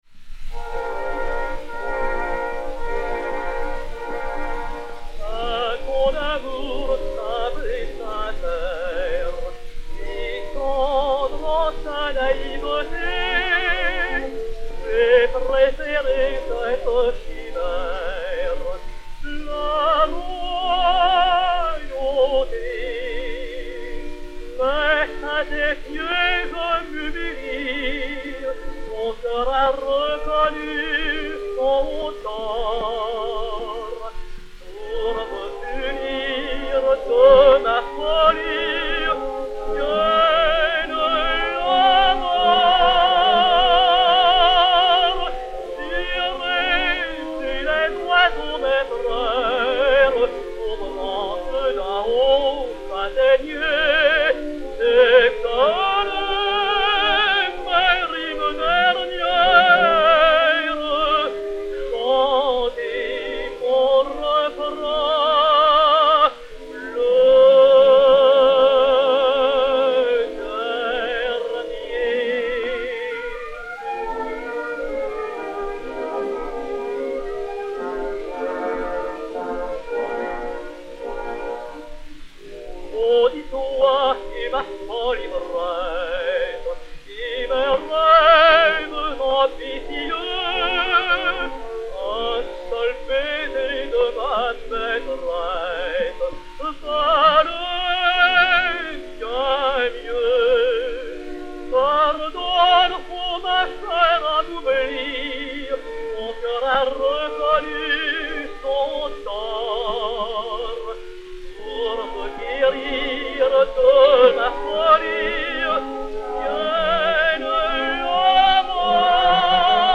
Edmond Clément (Clément Marot) et Orchestre